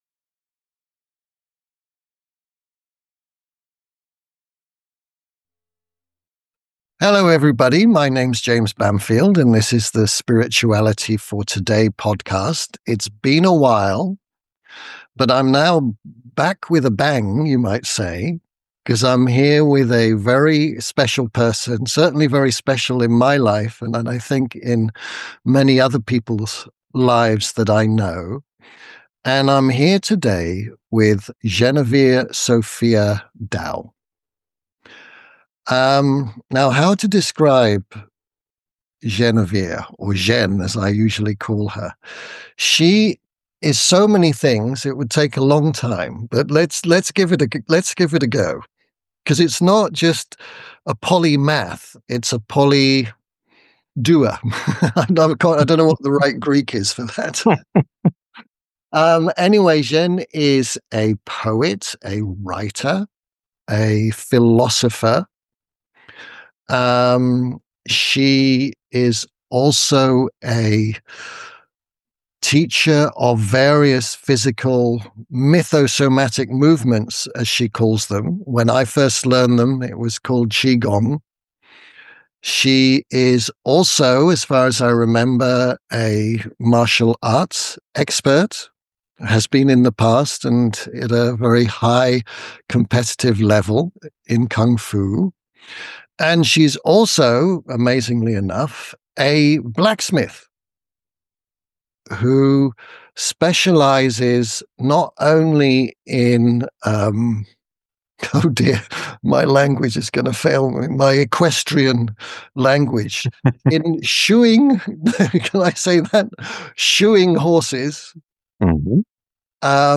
#15 Interview